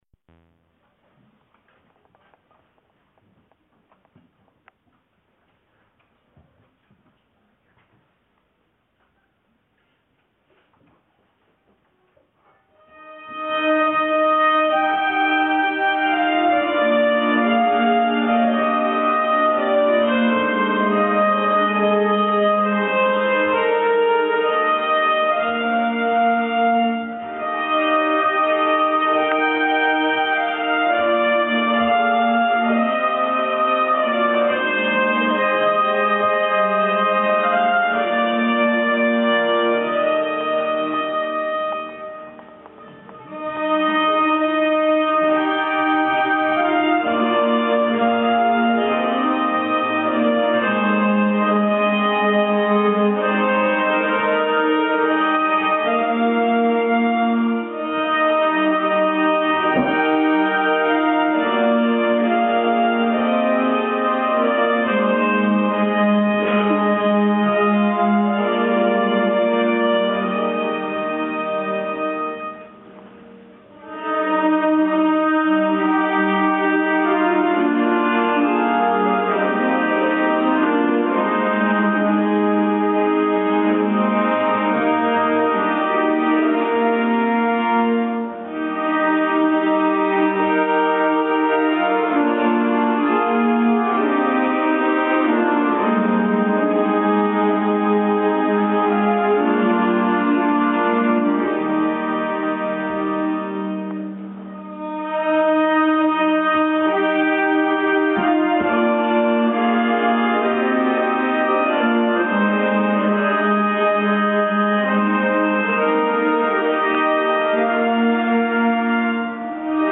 Stage de Nyckelharpa n°43
La partie concert
melanesien.mp3